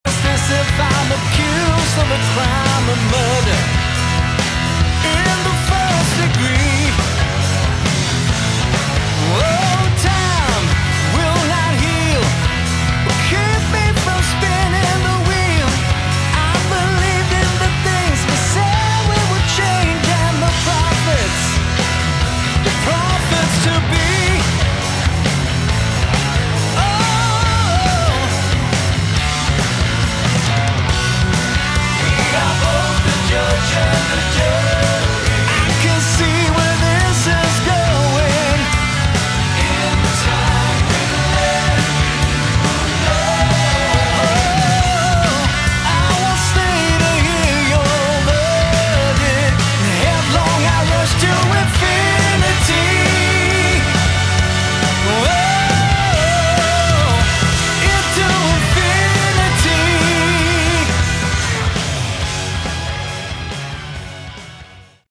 Category: Melodic Hard Rock
lead & backing vocals
lead & rhythm guitar, backing vocals
bass, backing vocals
drums, percussion, backing vocals
organ, keyboards, backing vocals